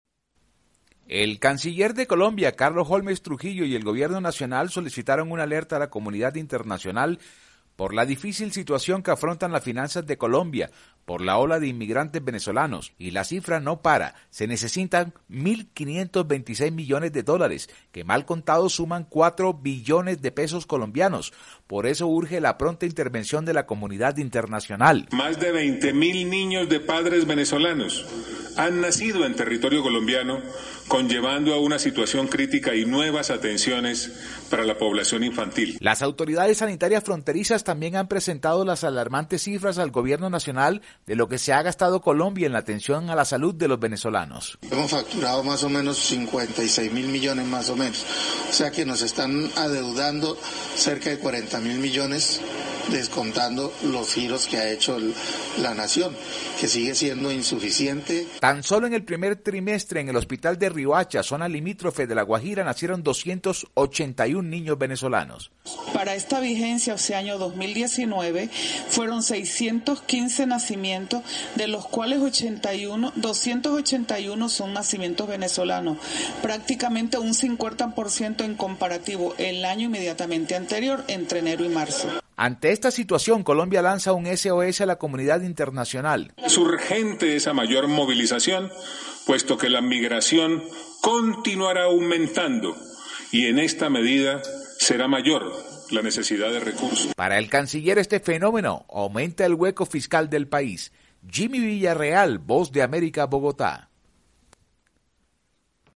VOA: Informe desde Colombia